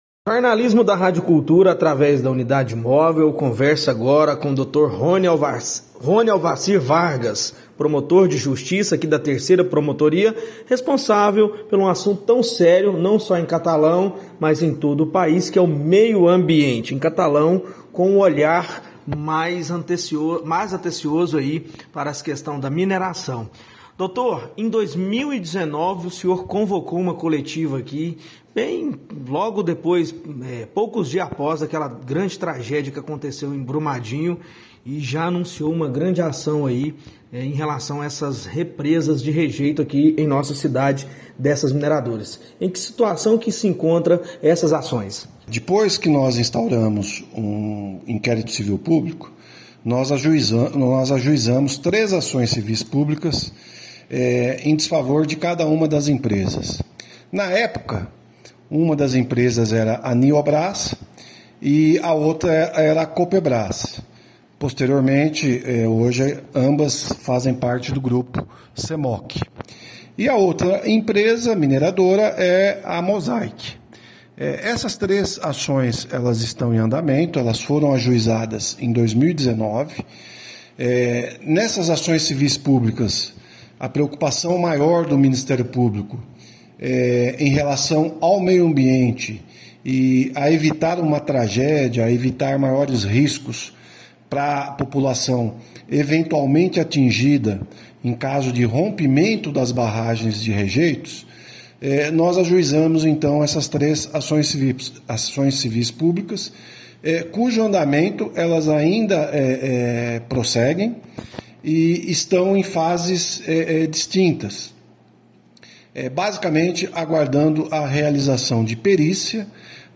O Blog do Badiinho entrevistou o promotor de Justiça Roni Alvarci Vargas, da Terceira Promotoria de Catalão.